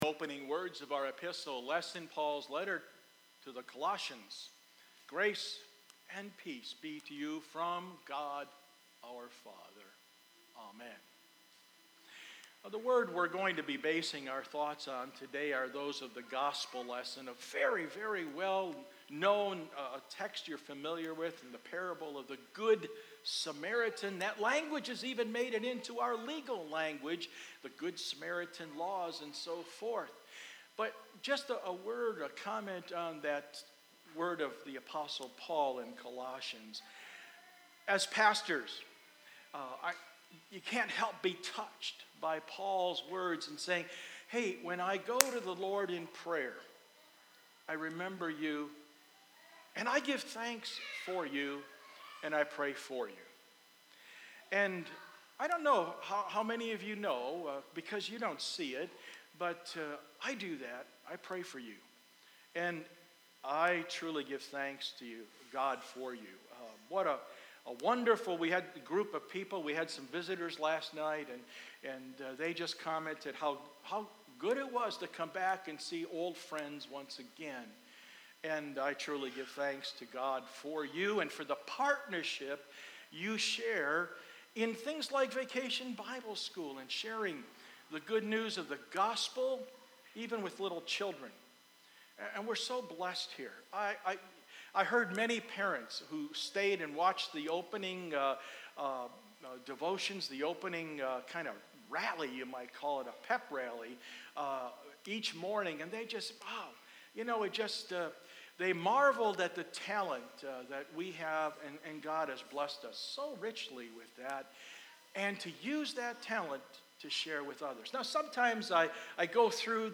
Sermon 7-28-19